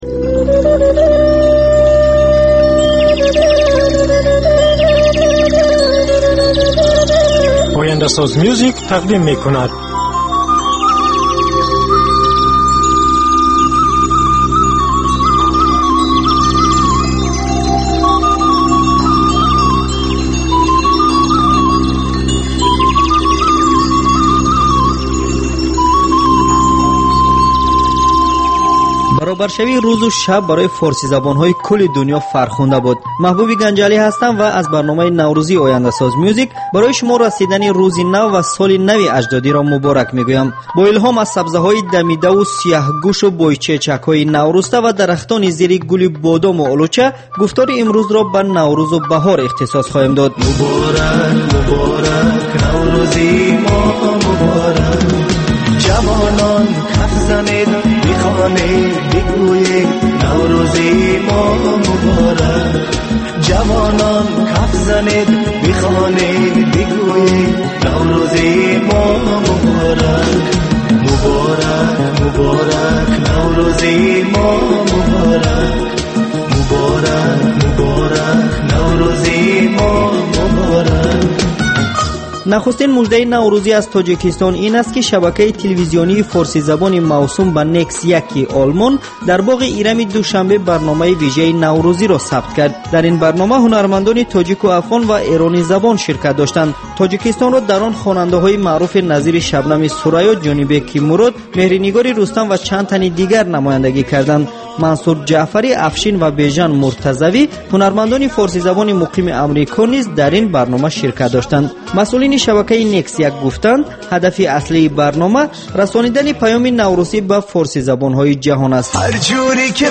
Барномаи мусиқӣ